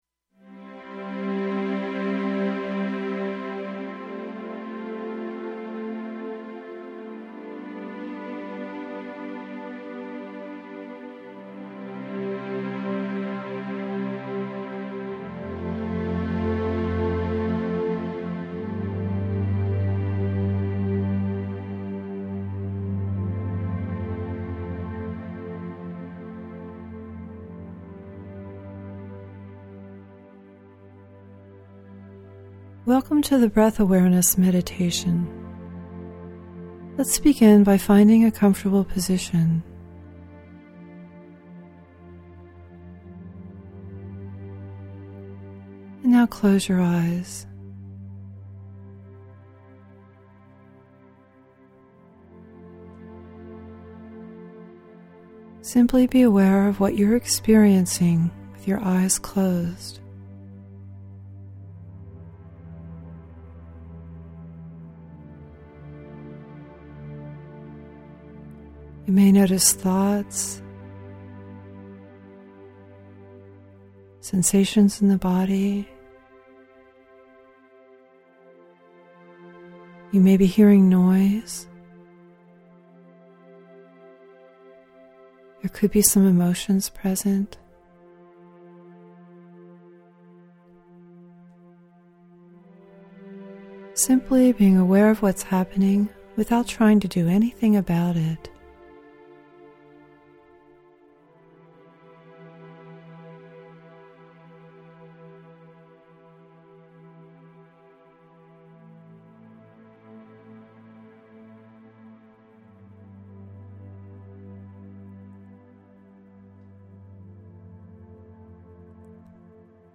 How to Listen to the Guided Meditation You will be voice-guided for 15 minutes, and then encouraged to continue on your own for 5 minutes more.
Week One Guided Meditation -- Breath Awareness (with music) Breath Awareness (without music ) Week One Support page -- use only if you had some difficulty meditating with the guided meditation or have questions about the practice.
1a-breath_with_music.mp3